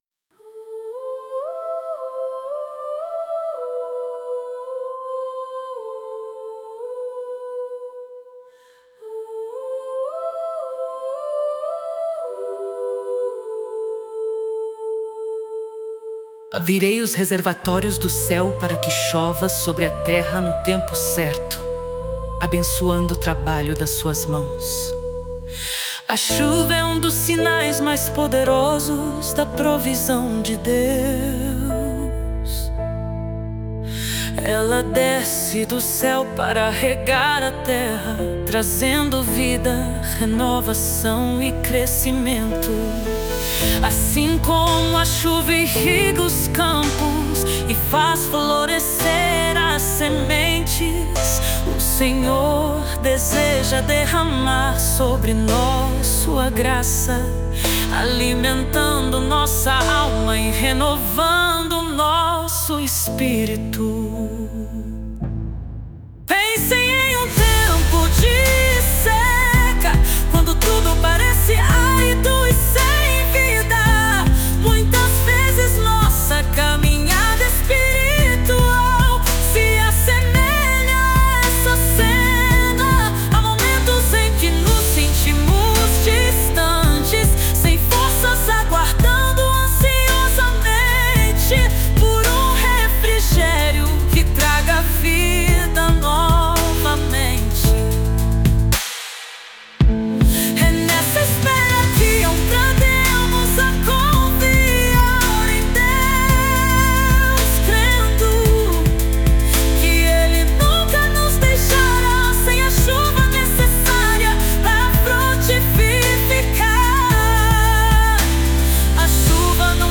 Baixar Música Grátis: Louvores com Inteligência Artificial!